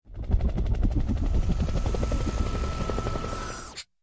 ENC_propeller_in.ogg